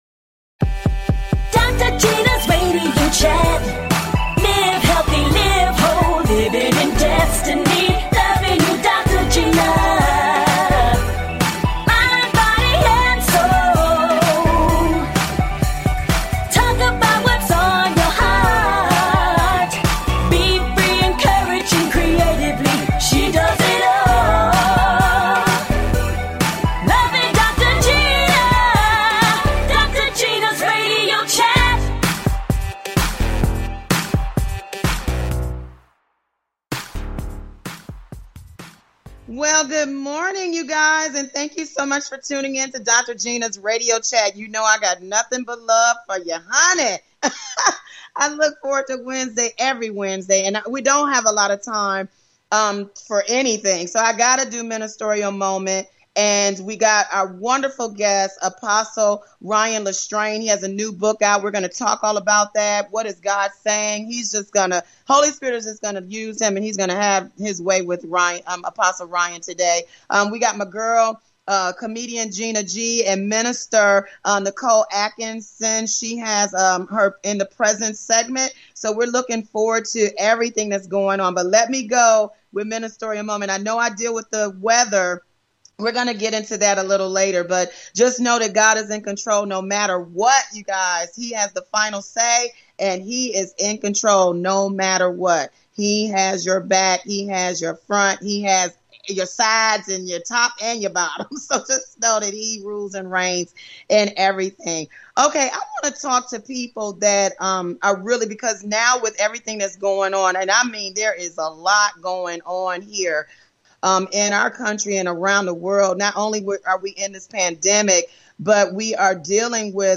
And full of laughter!
A talk show of encouragement.